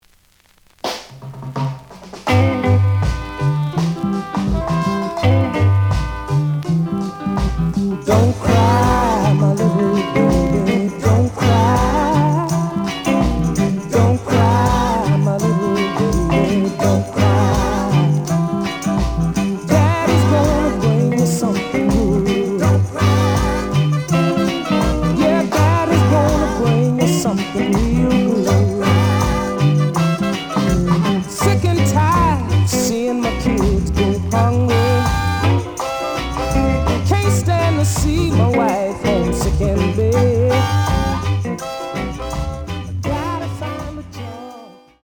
The audio sample is recorded from the actual item.
●Genre: Reggae